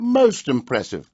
gutterball-3/Gutterball 3/Commentators/Jensen/jen_mostimpressive.wav at f3327c52ac3842ff9c4c11f09fb86b6fc7f9f2c5
jen_mostimpressive.wav